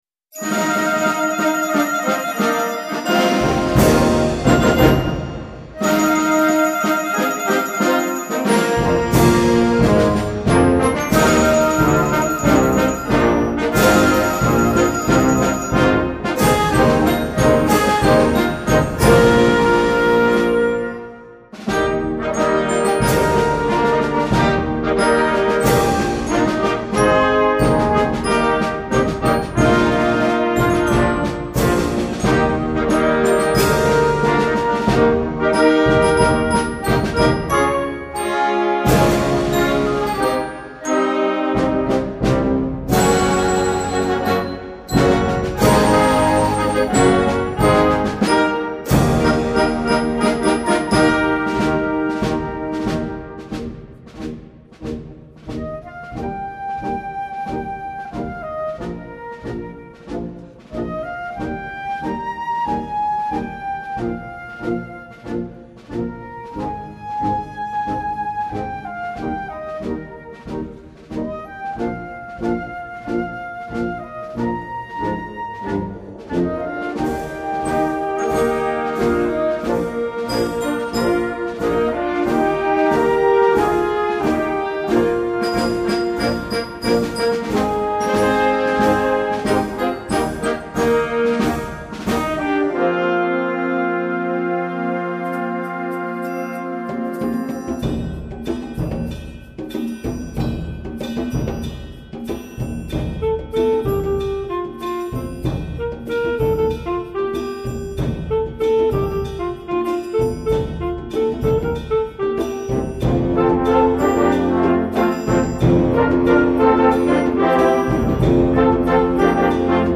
Concert Band and Optional Choir